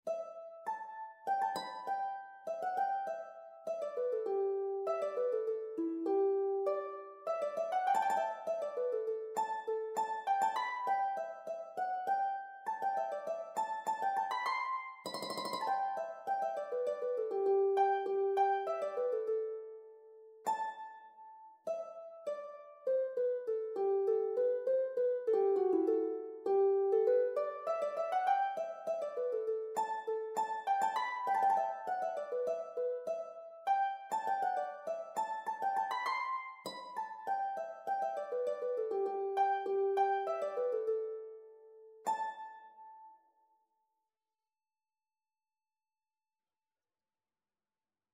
G major (Sounding Pitch) (View more G major Music for Harp )
2/4 (View more 2/4 Music)
~ = 100 Moderato
Harp  (View more Easy Harp Music)
Traditional (View more Traditional Harp Music)